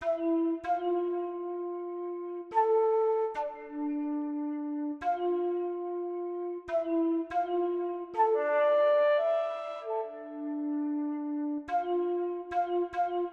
Watch Out_Flute.wav